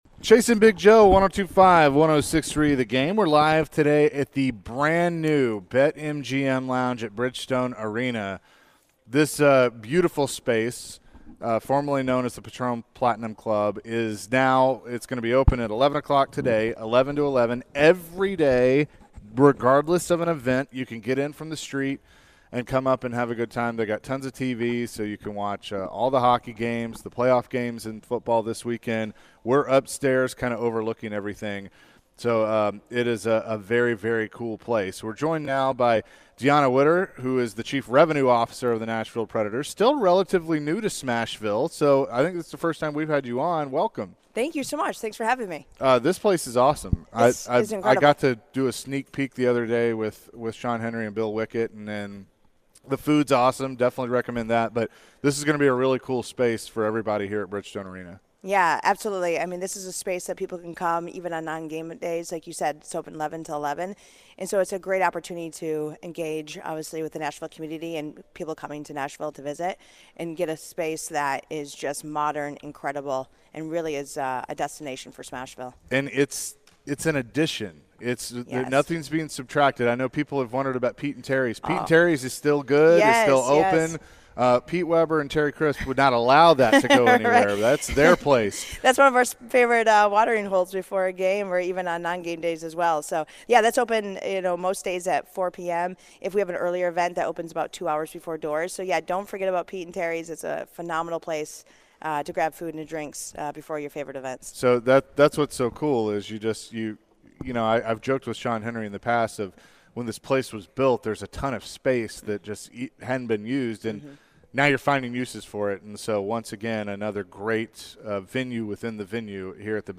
sit down in the new Bet MGM Sports Lounge, Restaurant, and Bar